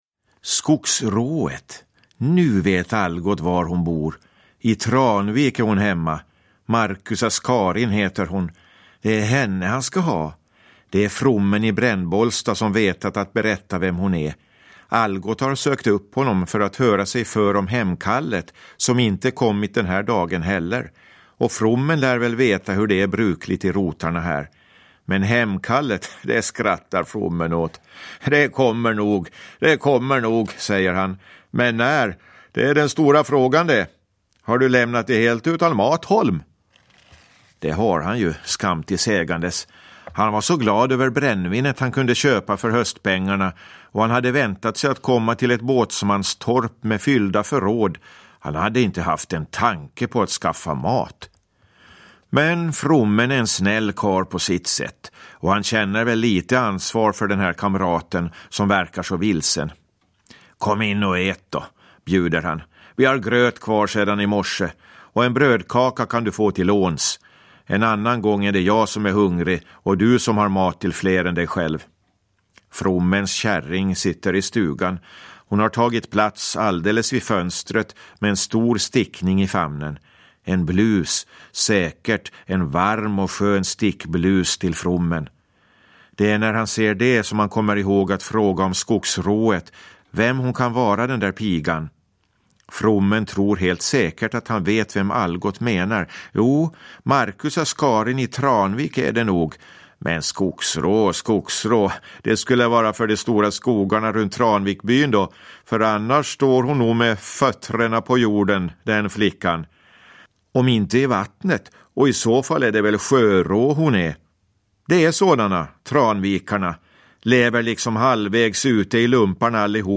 Algot – Ljudbok – Laddas ner